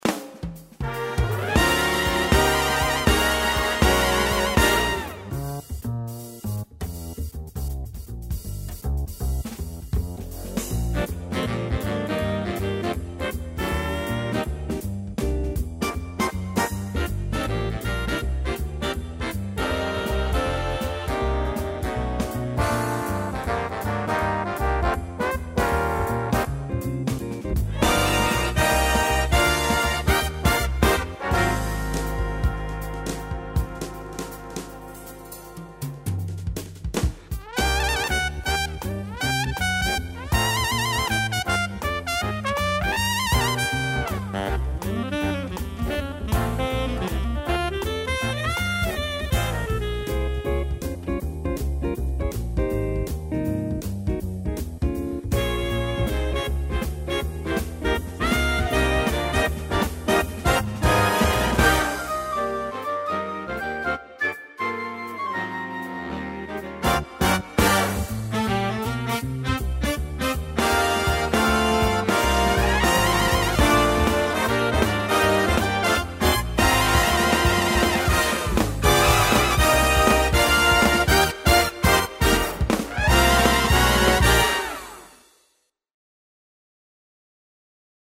Big Band
Style - Big Band.mp3